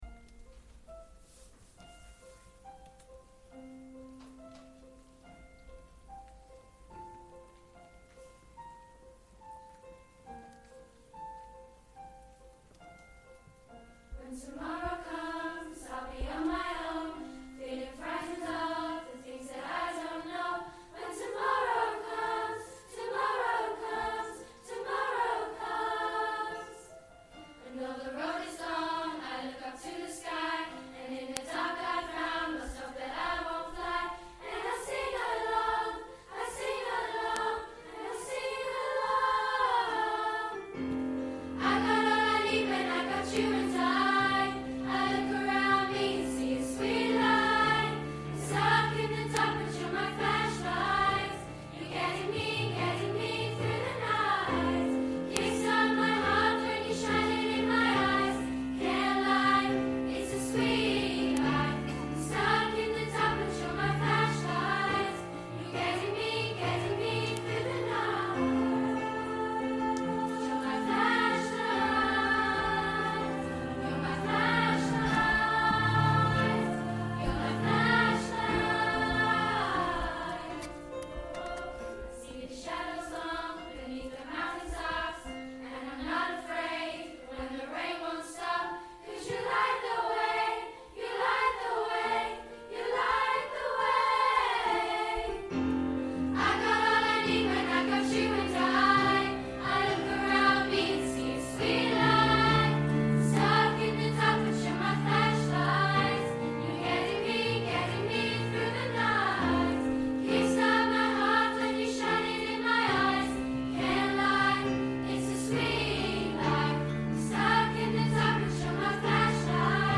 Flashlight - Junior Choir 2015